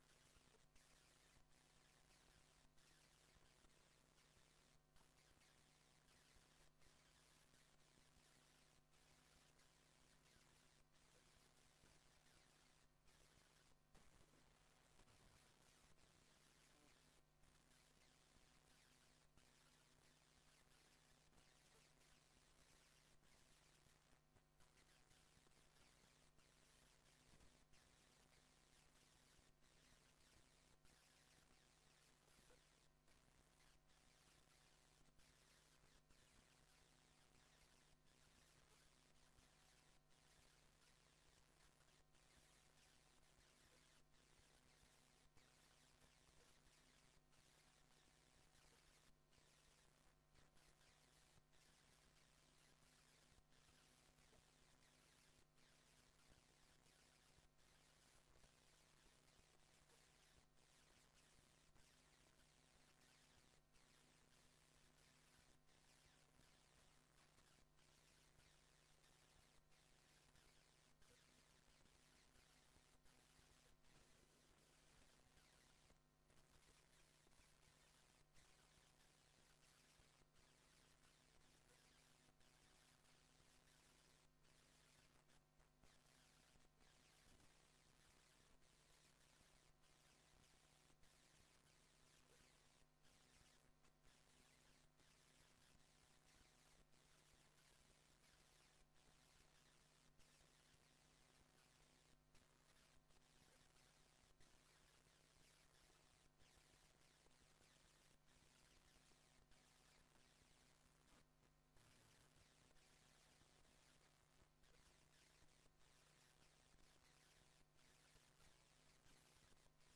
Gemeenteraad 10 december 2024 19:30:00, Gemeente Tynaarlo
Locatie: Raadszaal